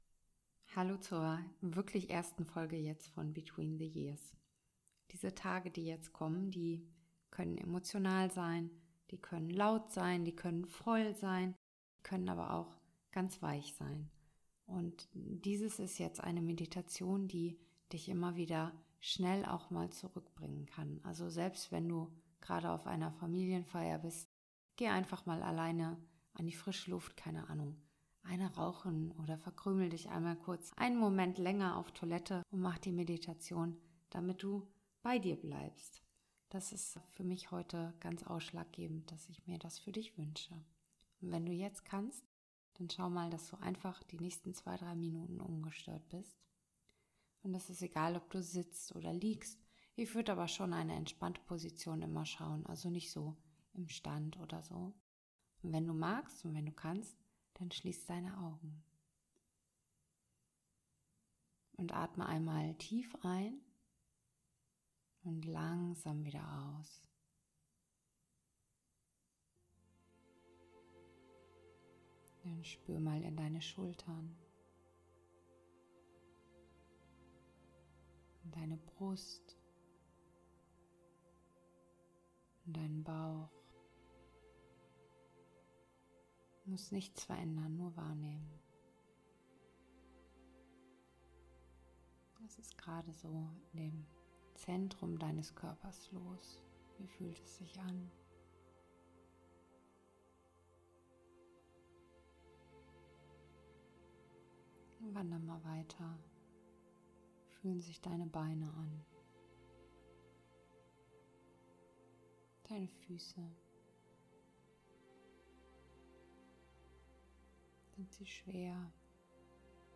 Between the Years - Eine Mini-Meditation zum Ankommen in dir
Ein ruhiger Moment, um bei dir anzukommen
Ein sanfter Start in diese kleine Begleitung zwischen den Jahren.